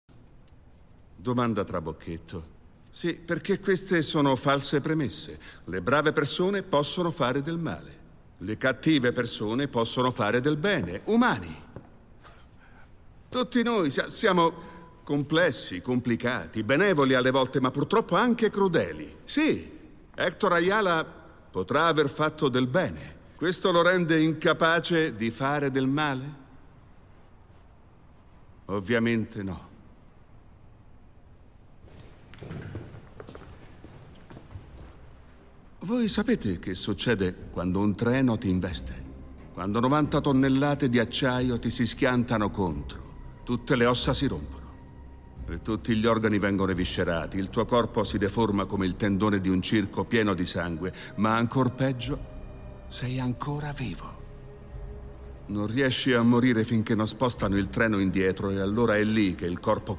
nel telefilm "Daredevil - Rinascita", in cui doppia John Benjamin Hickey.